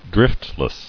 [drift·less]